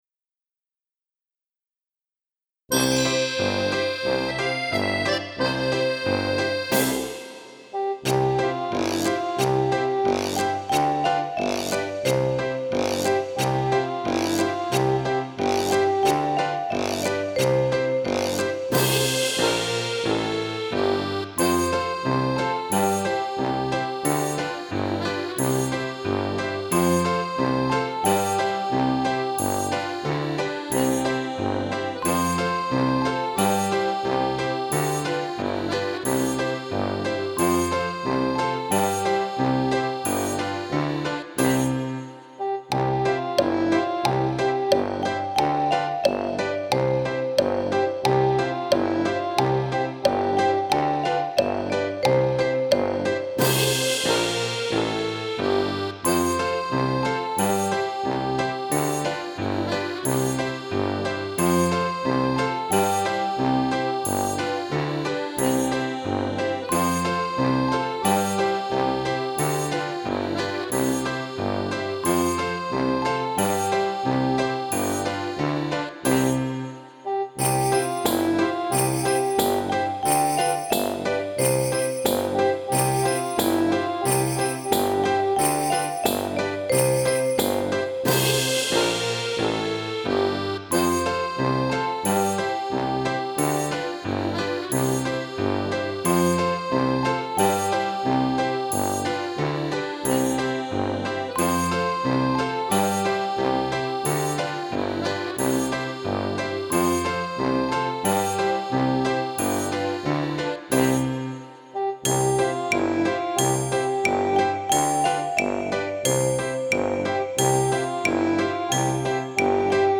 Скачать минус